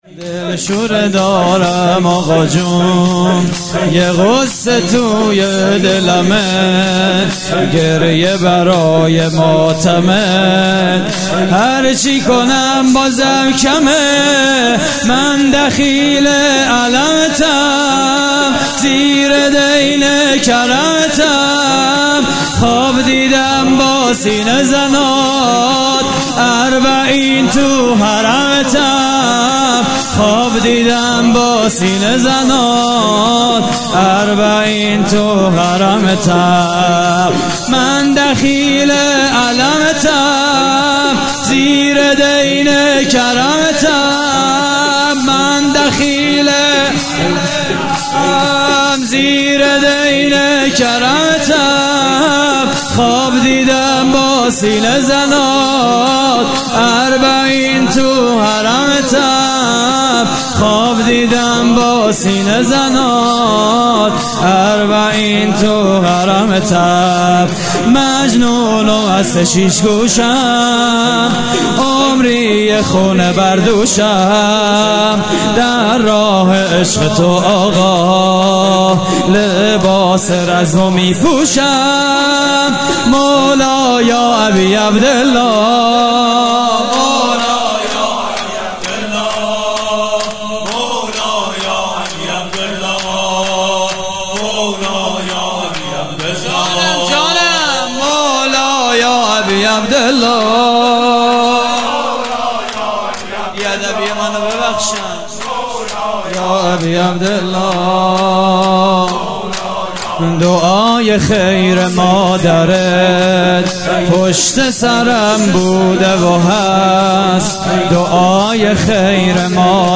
شور: دلشوره دارم آقاجون
مراسم عزاداری شهادت حضرت امیر (ع) (21 رمضان)